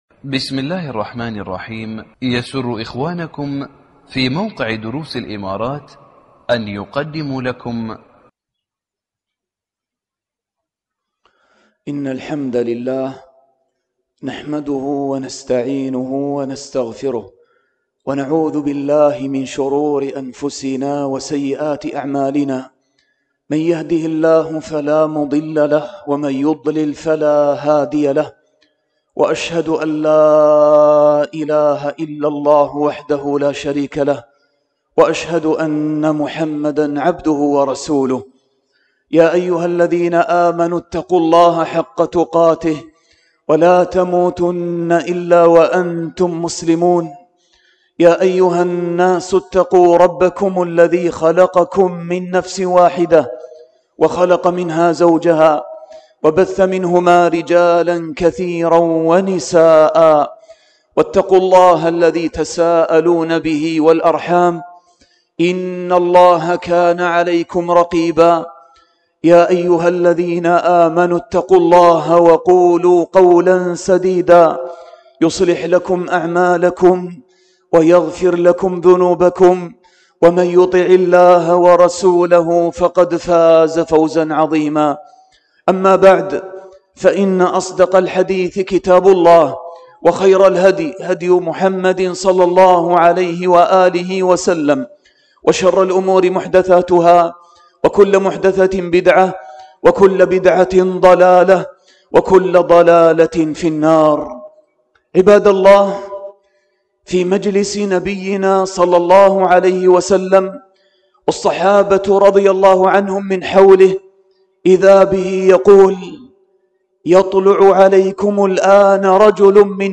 خطبة بعنوان: عبادات في ختام رمضان لـ